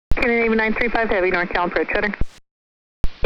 Airband AM Signals
One busy channel that has a strong signal around here is 310.8 MHz, SFO departures and arrivals from the south.
This is a signal from 310.8 MHz.